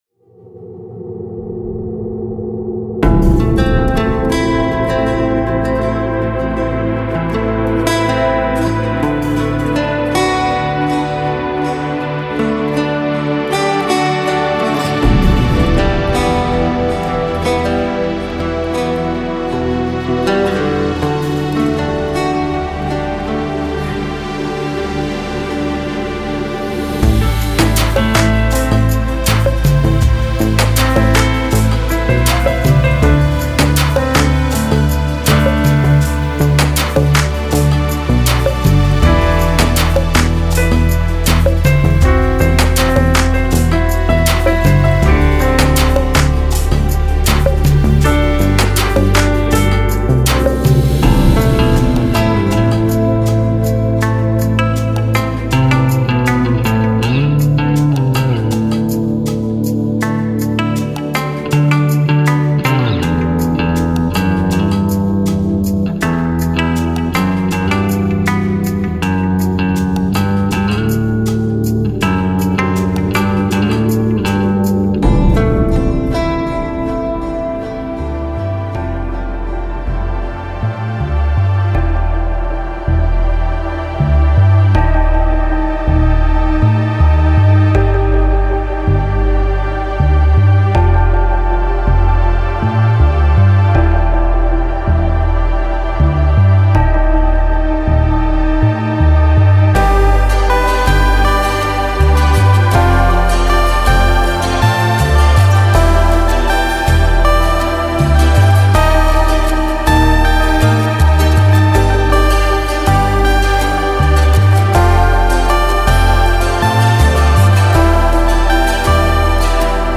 它包含来自7位专业歌手22种全无伴奏合唱，适用于各种音乐风格。